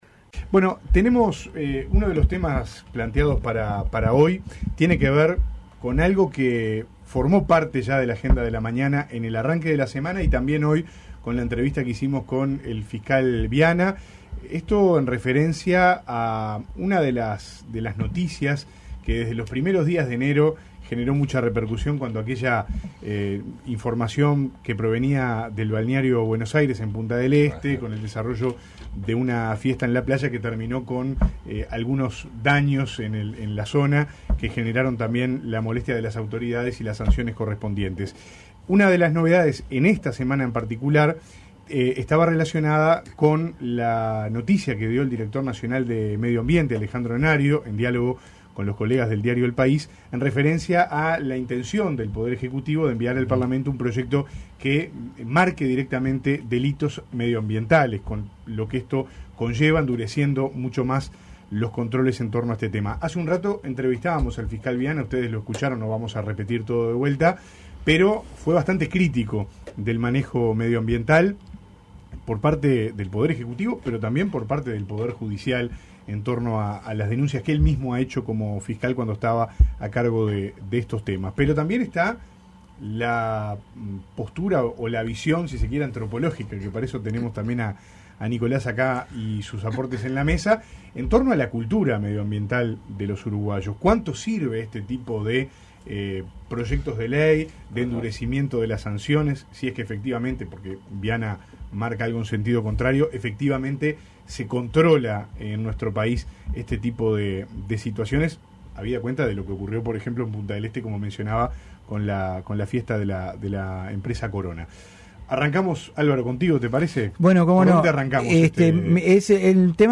Debatieron sobre el anuncio del Gobierno, que enviará al Parlamento un proyecto de ley para crear el delito penal ambiental.